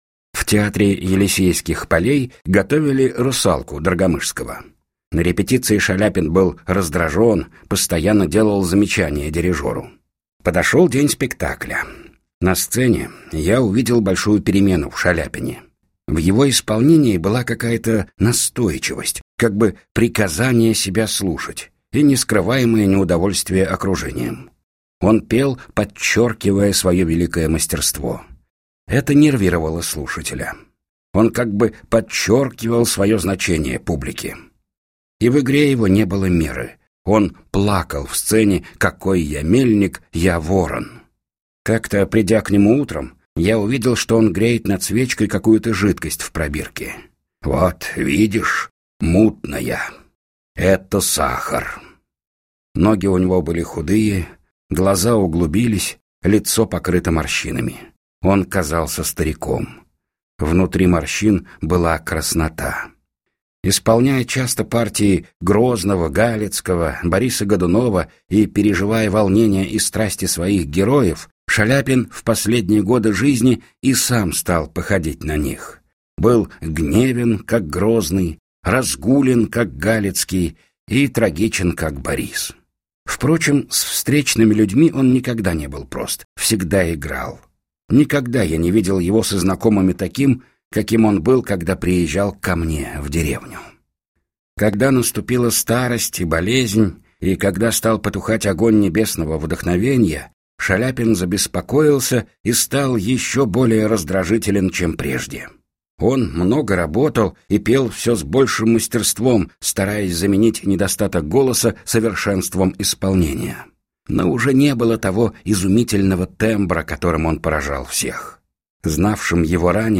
Демо